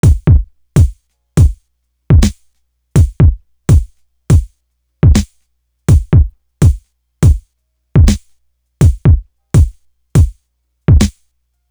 Impressions Drum.wav